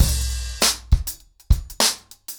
DrumkitRavage-100BPM_1.1.wav